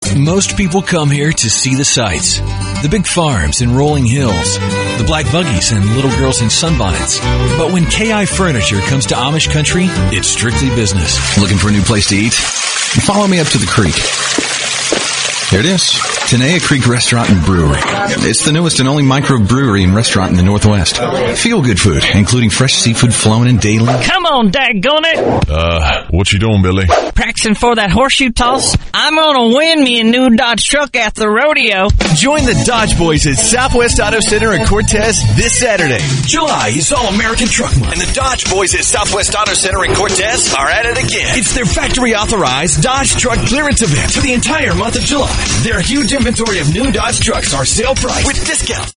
Commercials
Commercials - Various.mp3